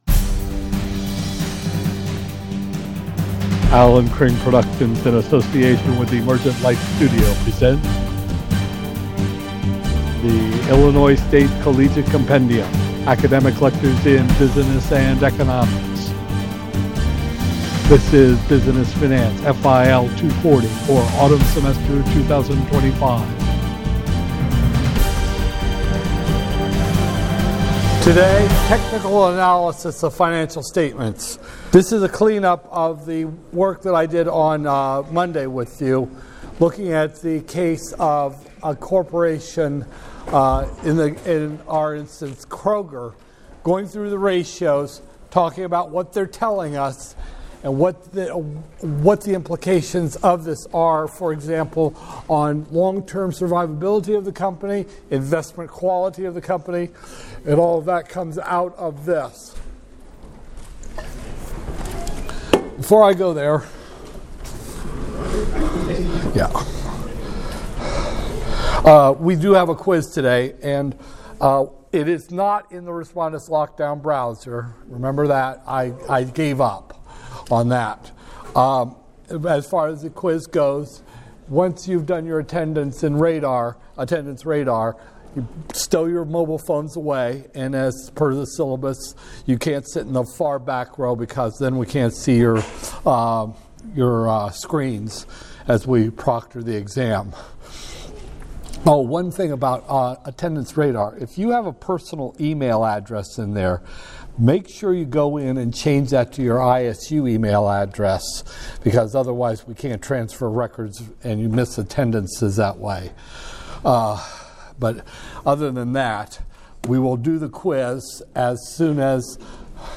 Business Finance, FIL 240-002, Spring 2025, Lecture 7